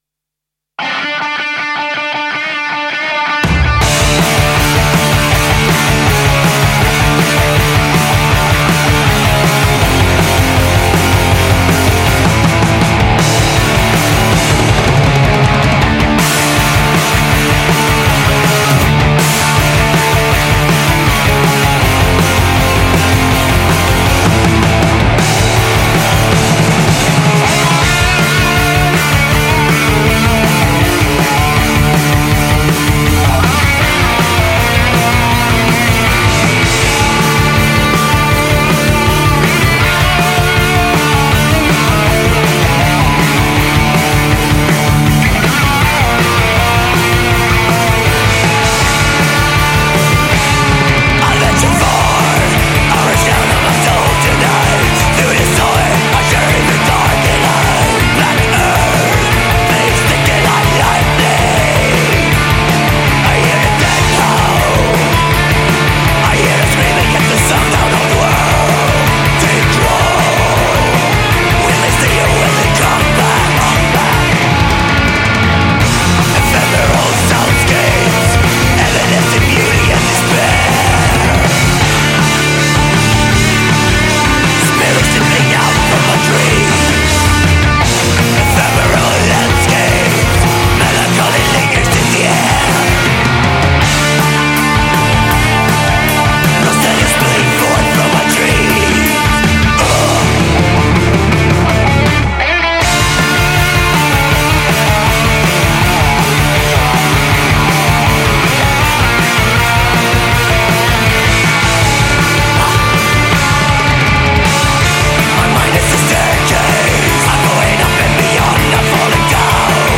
Todo ello con la ayuda de la música seleccionada (Heavy Metal, Thrash, Death, Gótico y variopinta).